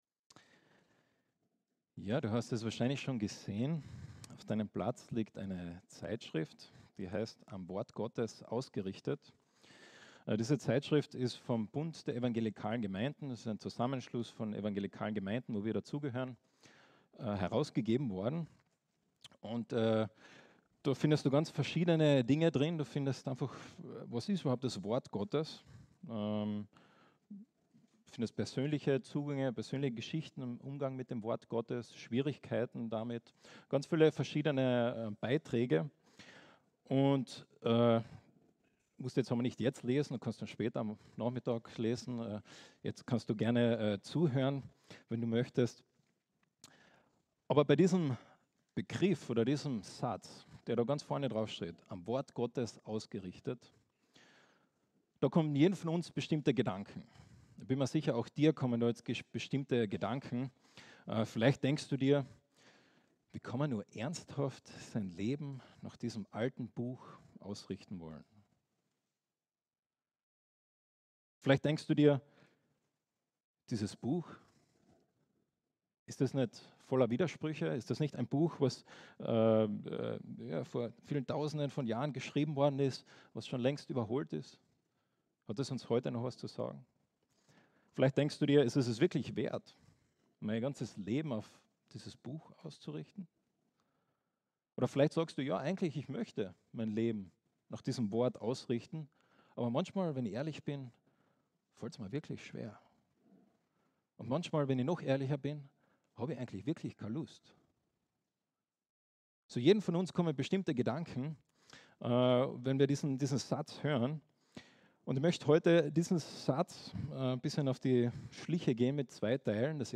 Aus der Serie: "Einzelpredigten 2022"